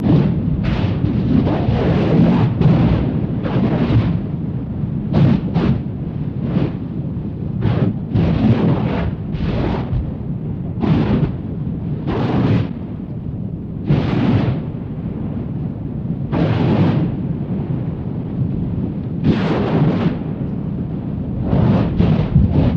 Звуки извержения вулкана
• Качество: высокое
Грохот извержения вулкана из жерла